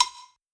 Cowbell (PILOT).wav